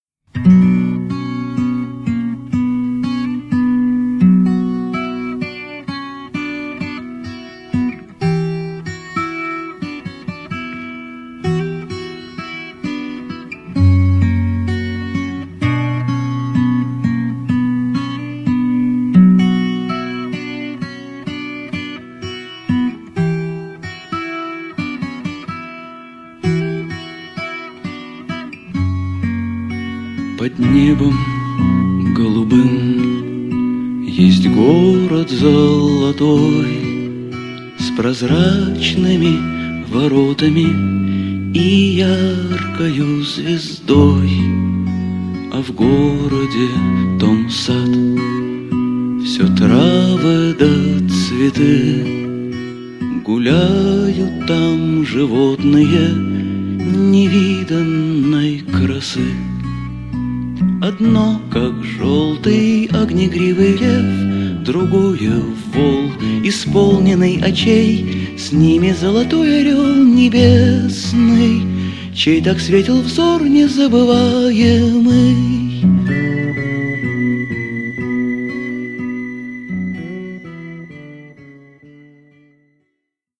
• Качество: 256, Stereo
лирика